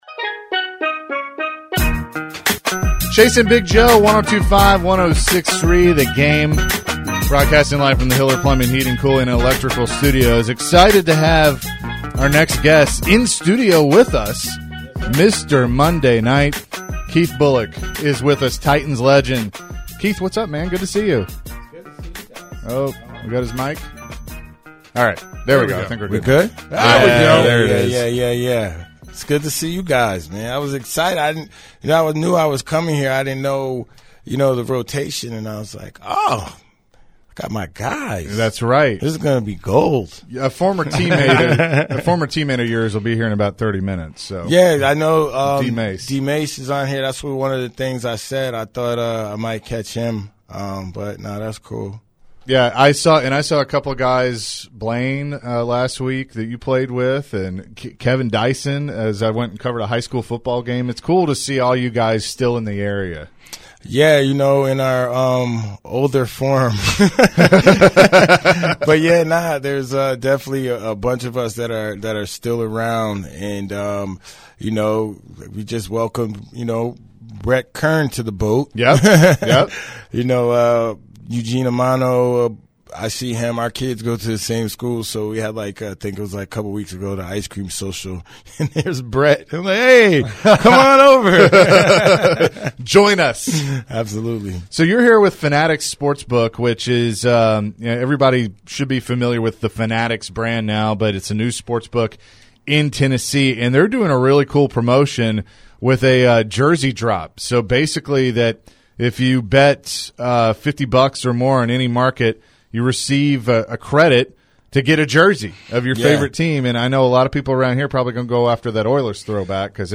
on the show in the studio. He talked about his new partnership with Fanatics Sportsbook. Plus Keith also goes down memory lane about some of the best moments in Titans history.